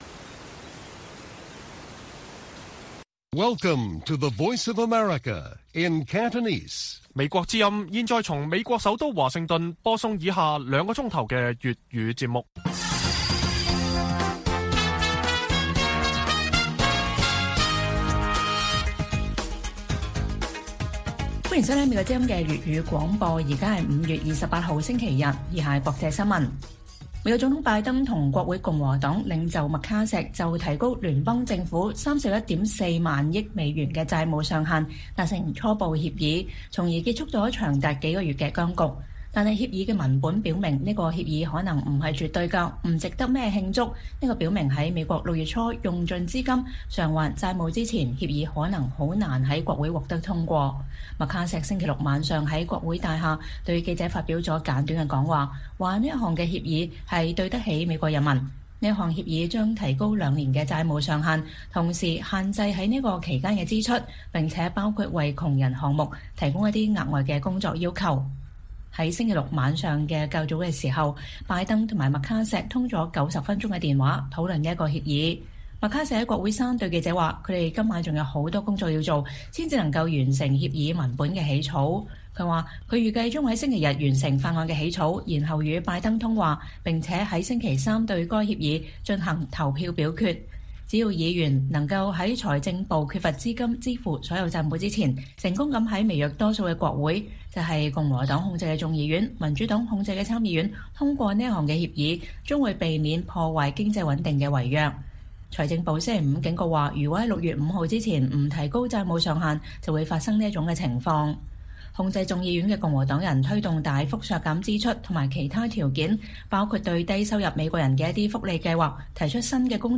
粵語新聞 晚上9-10點: 拜登和麥卡錫達成提高美國債務上限的初步協議